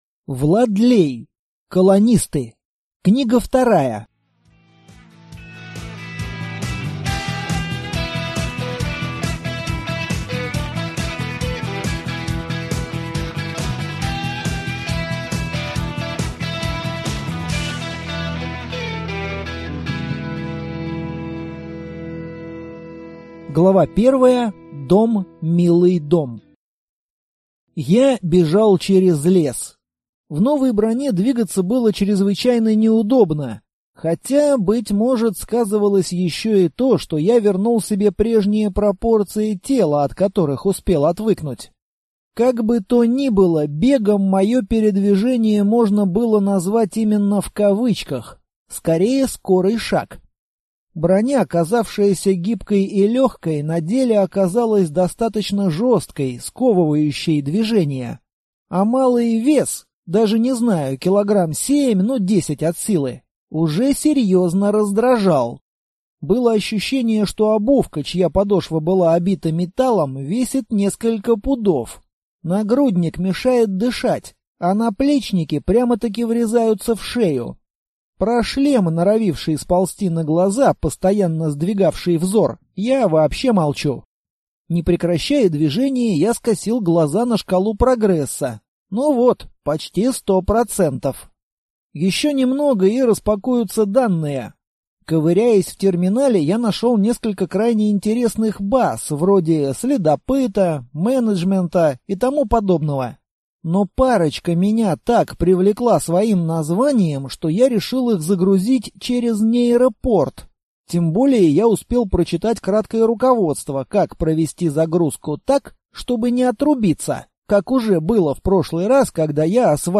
Аудиокнига Колонисты. Книга 2 | Библиотека аудиокниг